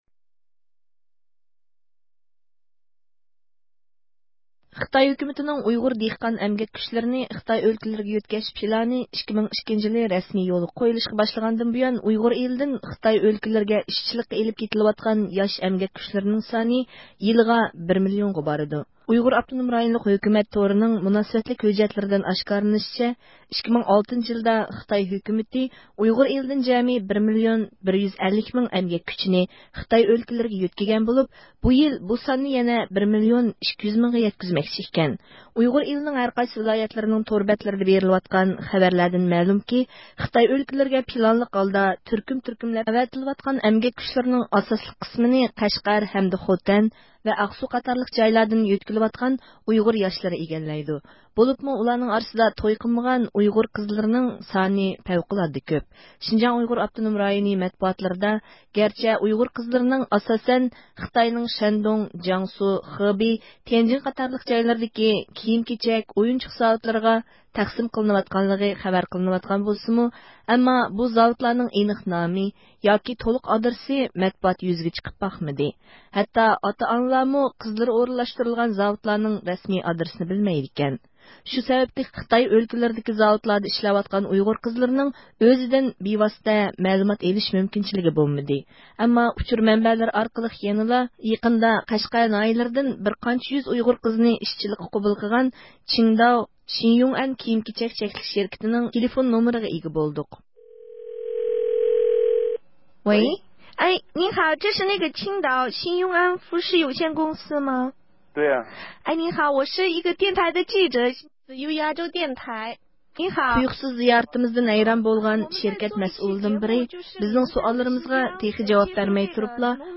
تۇيۇقسىز زىيارىتىمىزدىن ھەيران بولغان شىركەت مەسئۇلىدىن بىرى بىزنىڭ سوئاللىرىمىزغا تېخى جاۋاب بەرمەي تۇرۇپلا قايتۇرۇپ سوئال سوراشقا باشلىدى: